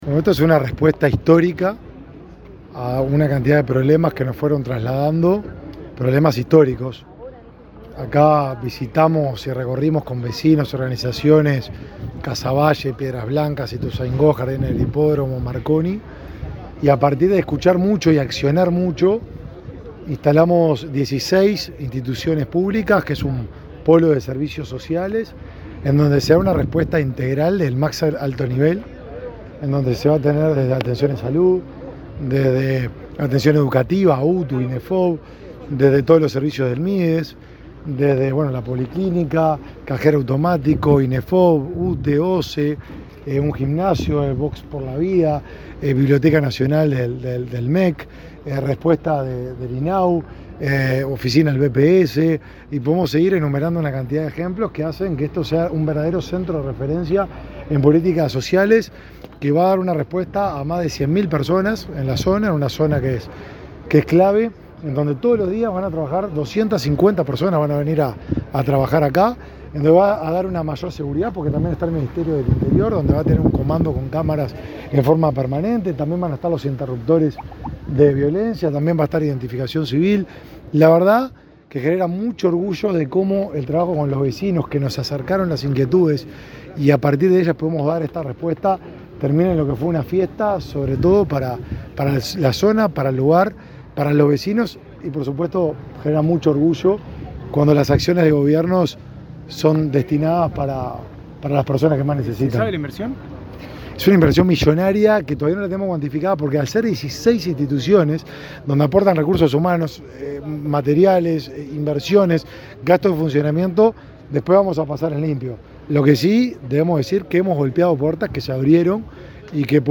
Declaraciones del ministro de Desarrollo Social, Martín Lema
El ministro de Desarrollo Social, Martín Lema, dialogó con la prensa, luego de participar de la inauguración del Centro de Referencia de Políticas